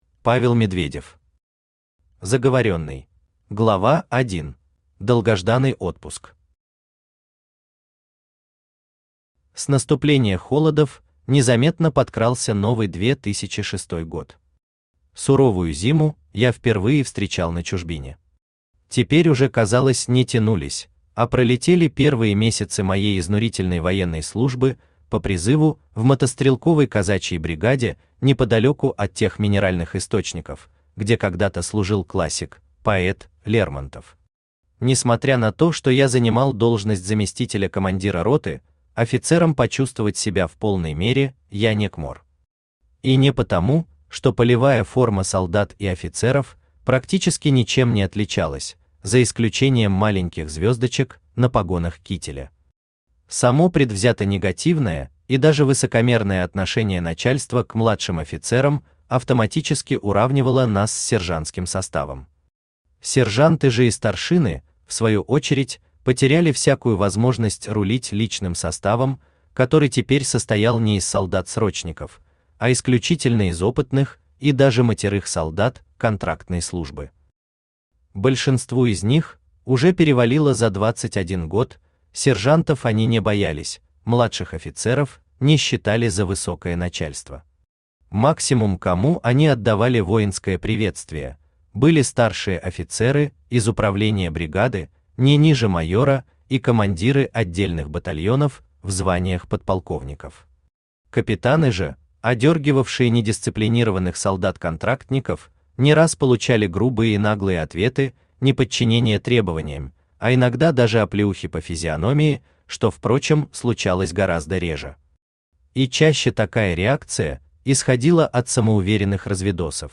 Аудиокнига Заговоренный | Библиотека аудиокниг
Aудиокнига Заговоренный Автор Павел Михайлович Медведев Читает аудиокнигу Авточтец ЛитРес.